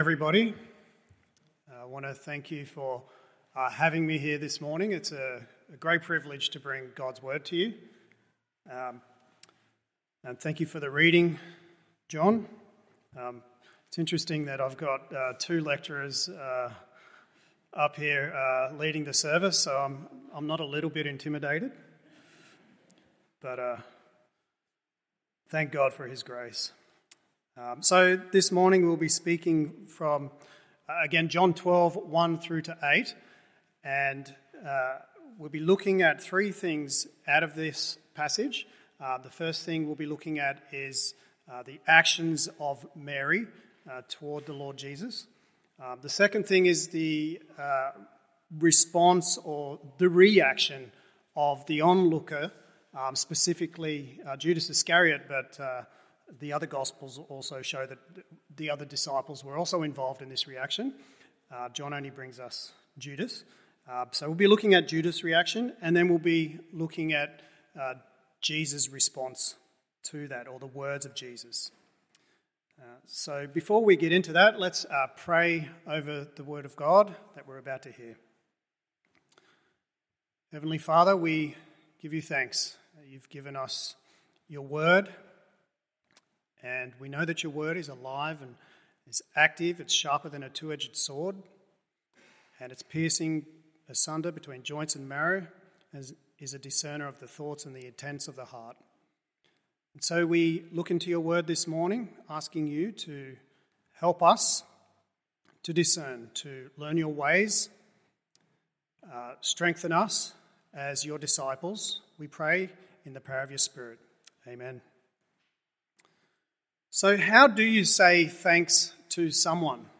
Morning Service John 12…